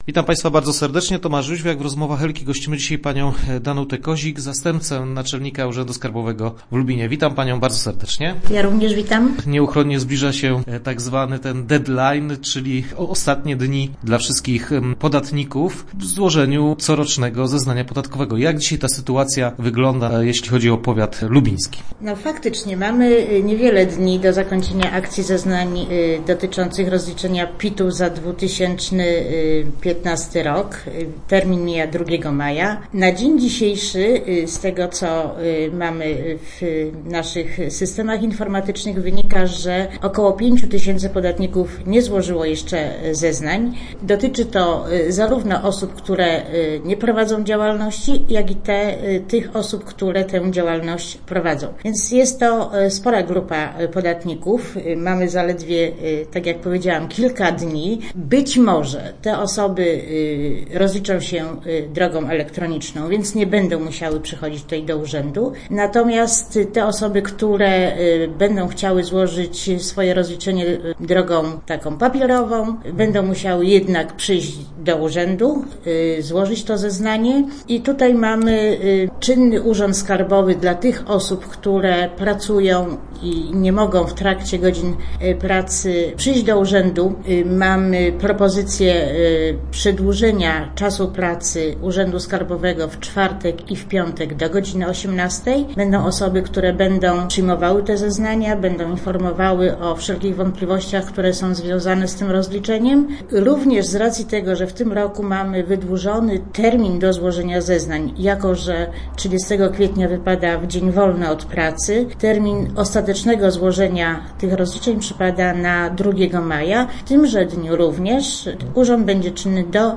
Sport
Start arrow Rozmowy Elki arrow Rozliczamy się do 2 maja